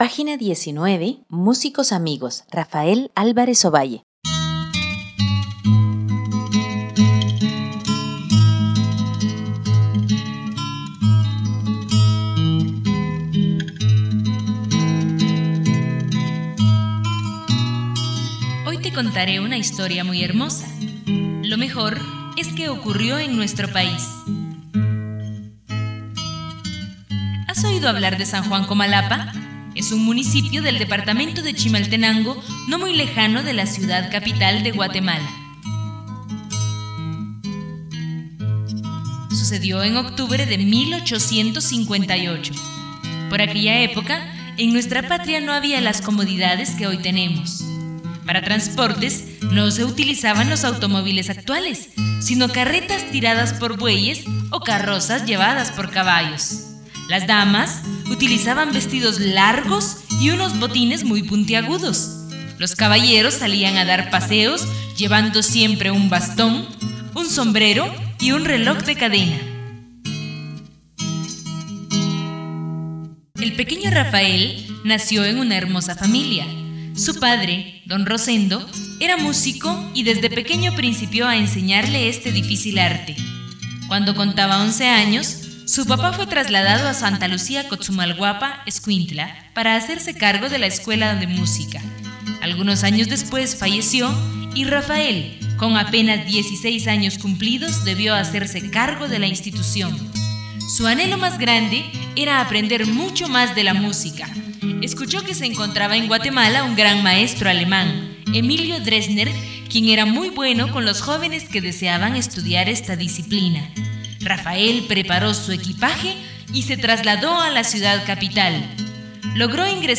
Rafael Álvarez Ovalle, cuento